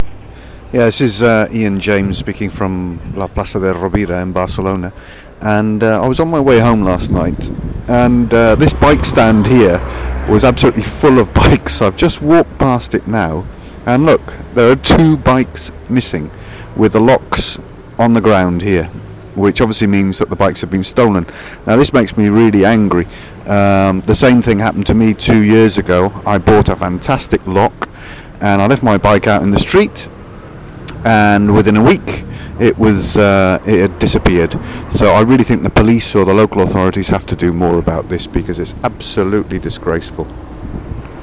from the Plaça de Rovira in Barcelona